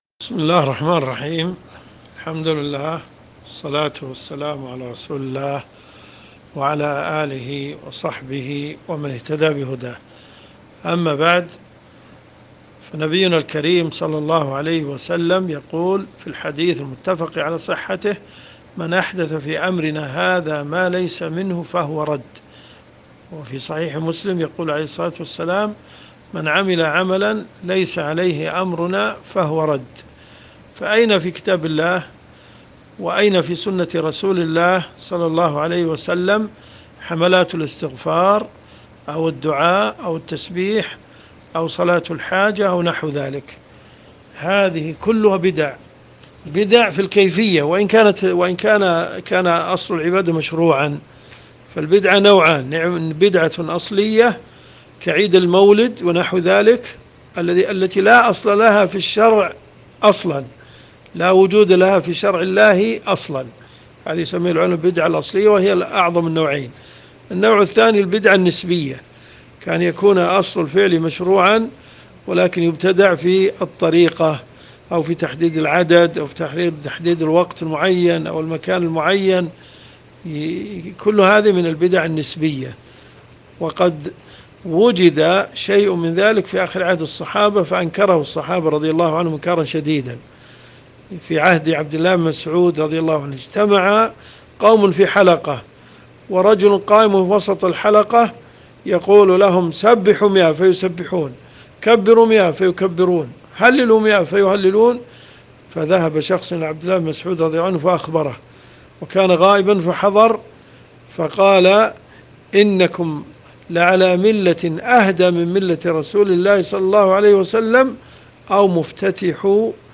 الفتاوى